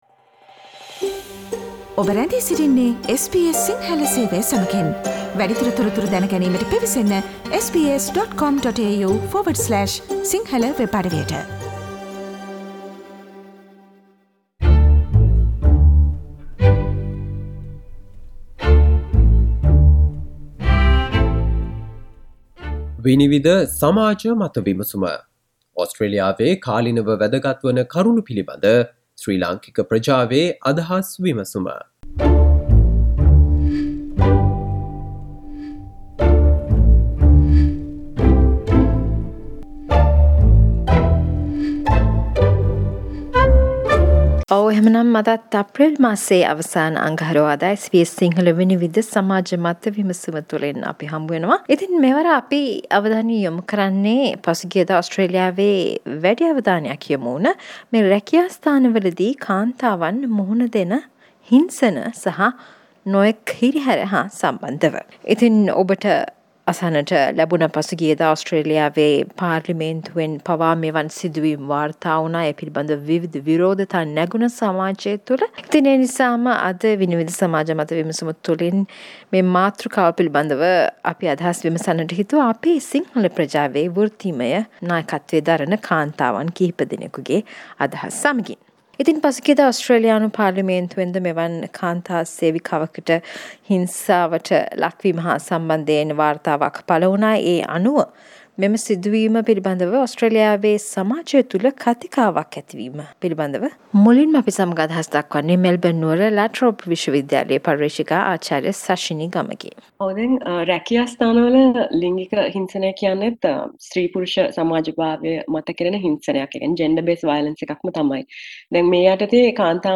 SBS Sinhala Vinivida Monthly panel discussion on how women are harassed at the workplace
Listen to ideas from Sri Lankan professional women in Australia talking about harassment at the workplace. Harassment can be in many forms and women from migrant backgrounds lack awareness of it.